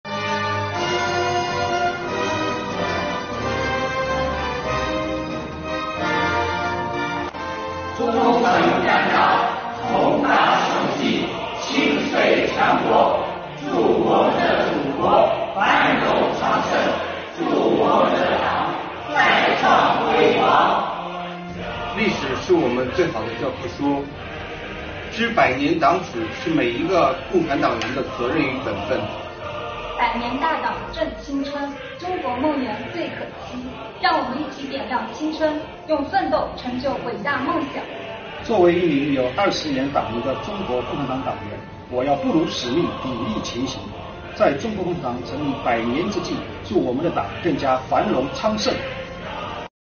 为热烈庆祝建党百年，闵行区税务局的党员干部职工们结合学党史知识、过“政治生日”，践岗位承诺等，以满腔的热情向党诉说自己的心声，让我们一起来听听这些跨越时空的“初心对话”吧！